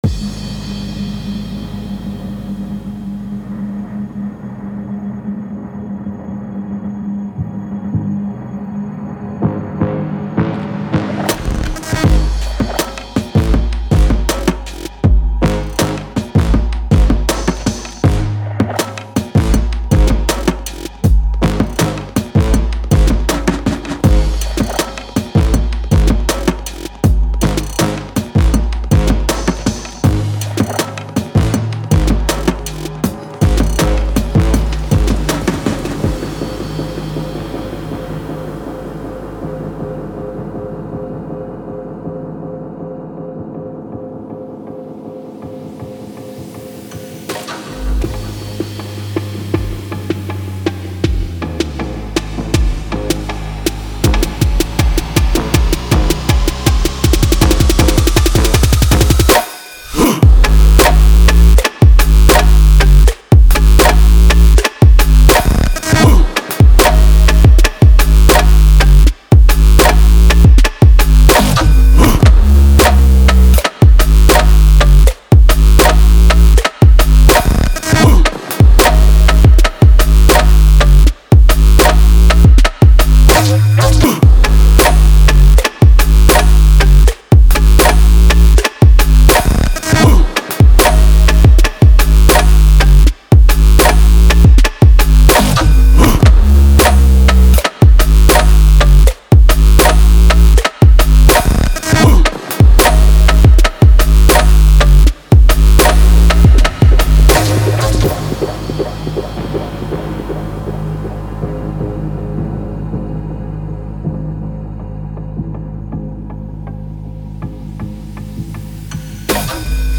受到嘻哈，陷阱，说唱，神经等等的影响。“
• 81 Thick drum hits
• 18 Unorthodox drum loops (Full bounces and stem bounces)
• 20 Fat 808’s
• 15 Gnarly synth one shots
• 20 Tension Building SFX
• Tempos – 160BPM